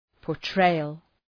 Προφορά
{pɔ:r’treıəl}